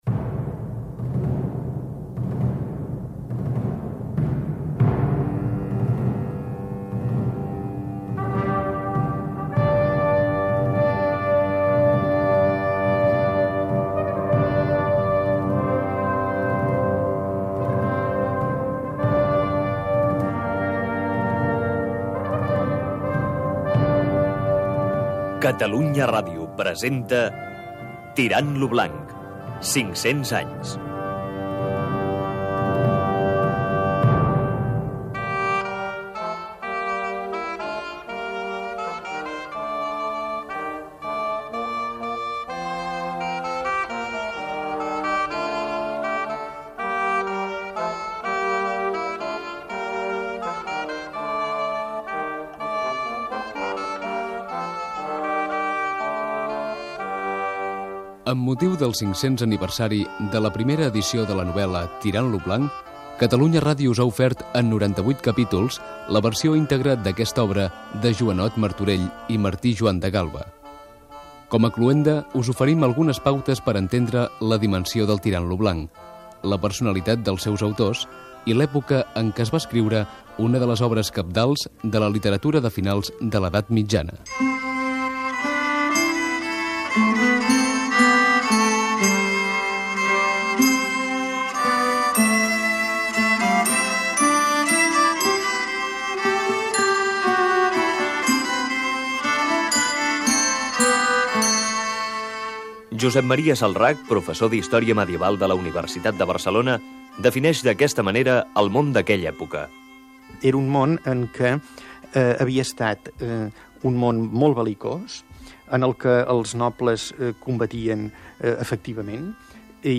Últim capítol dedicat a contextualitzar l'època en que va ser escrita l'obra. Careta del programa i participació dels professors universitaris